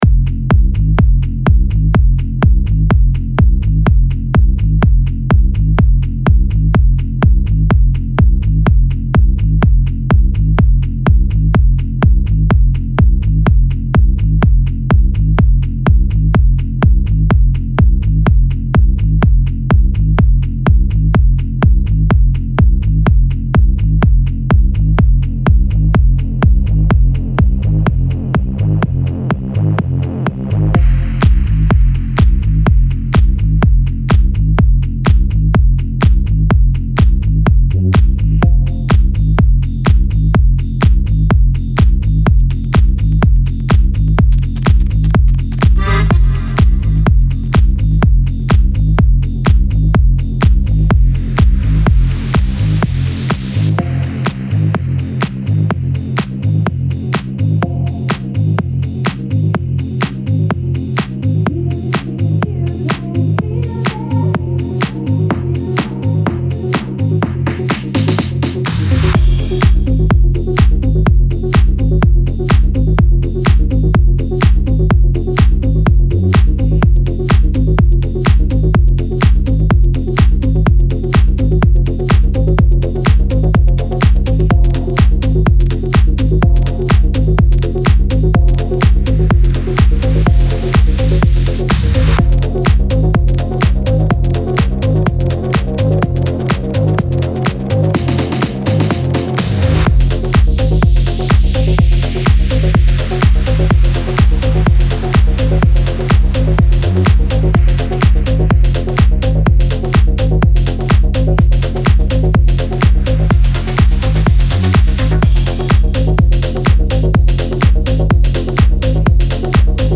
• Жанр: Dance, Techno